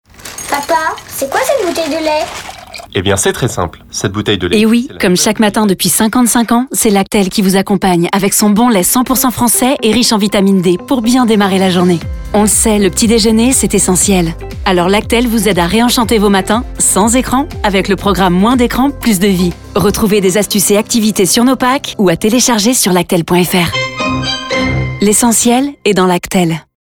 Spot radio pour les 30 ans de LACTEL Production: Le Jolie Prod